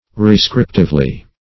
rescriptively - definition of rescriptively - synonyms, pronunciation, spelling from Free Dictionary Search Result for " rescriptively" : The Collaborative International Dictionary of English v.0.48: Rescriptively \Re*scrip"tive*ly\, adv.
rescriptively.mp3